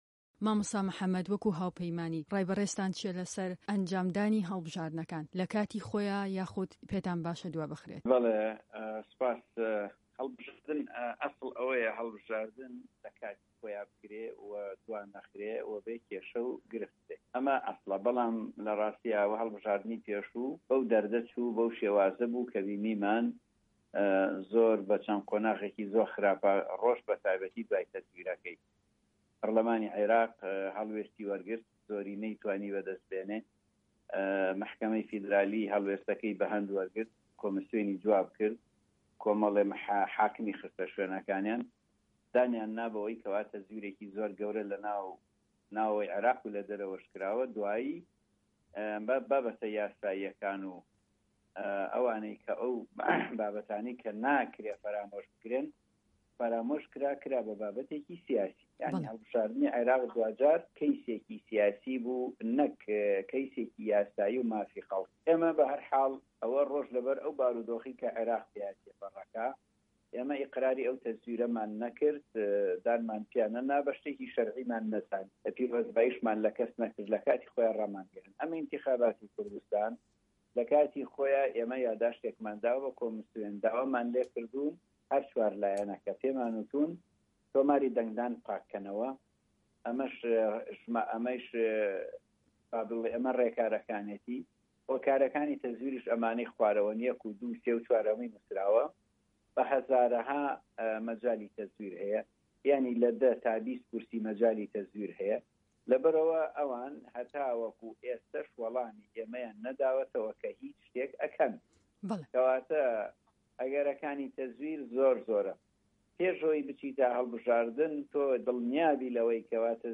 وتووێژه‌كه‌ی